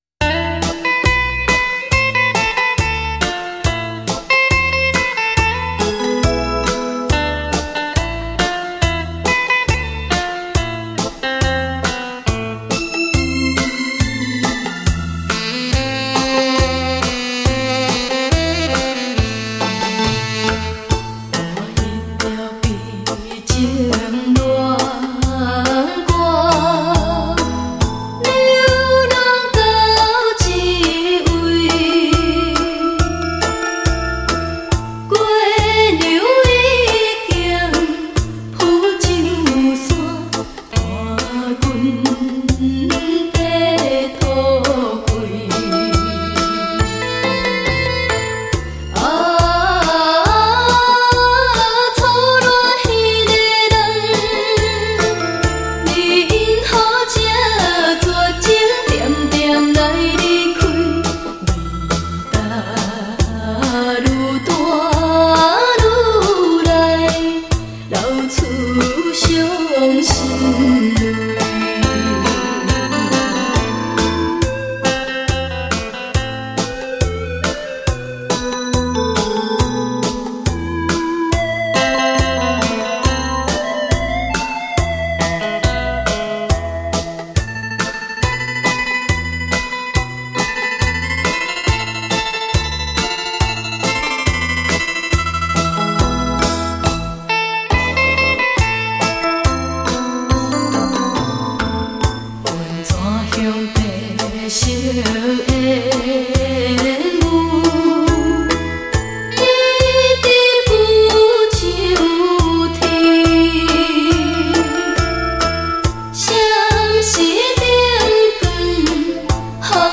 重新编曲 全新演唱
女声实力歌手 保证让您百听不厌
12首台湾经典情歌一次爱个够